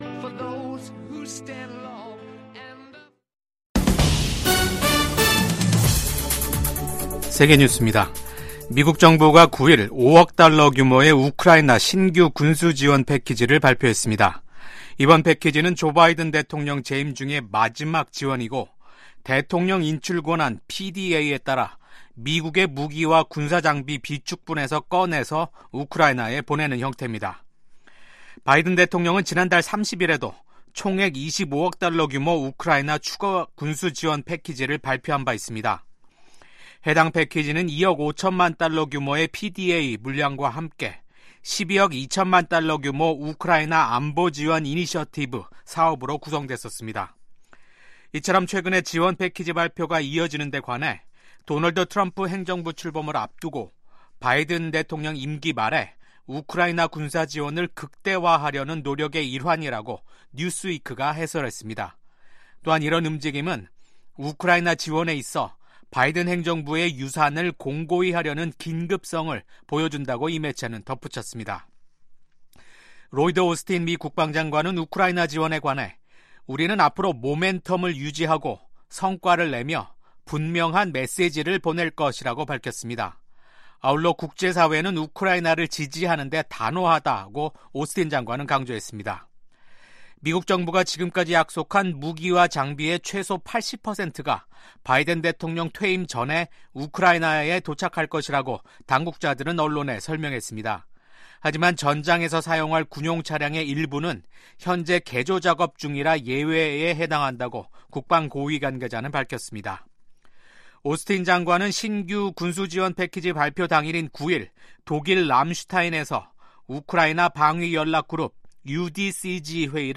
VOA 한국어 아침 뉴스 프로그램 '워싱턴 뉴스 광장'입니다. 미국 국방부는 최근 북한의 탄도미사일 발사를 인지하고 있으며 동맹과 긴밀히 협력하고 있다고 밝혔습니다. 북한의 극초음속 중거리 탄도미사일 발사에 대한 국제사회의 비판이 이어지는 가운데 유엔은 북한의 탄도미사일 발사가 안보리 결의의 명백한 위반이라고 지적했고, 우크라이나는 북한과 러시아의 협력을 막기 위한 공동의 노력과 엄중한 제재 이행의 필요성을 강조했습니다.